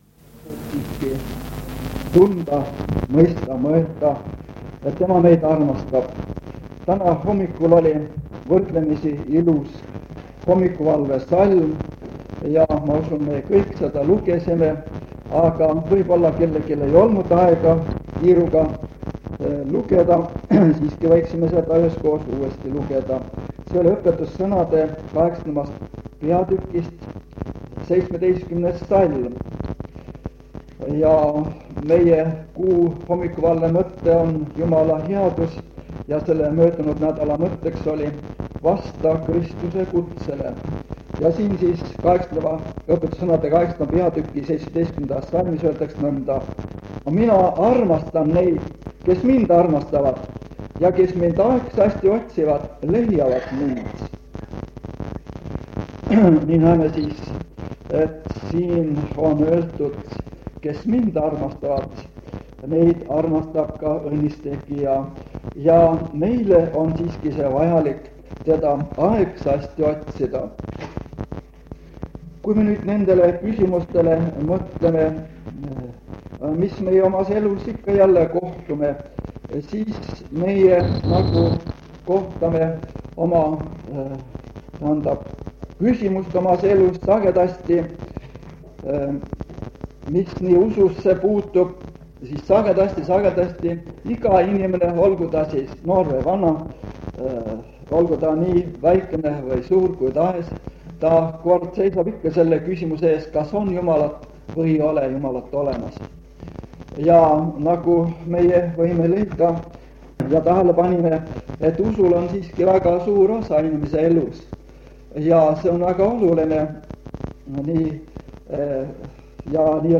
Jutlus vanalt lintmaki lindilt.